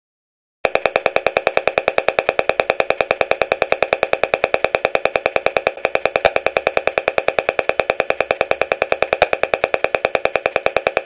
Si bien nuestros oidos no puden escuchar las radiaciones (Microondas) de los artefactos inalámbricos, un analizador de frecuencia es capaz de convertirlas en audio.
wifi.mp3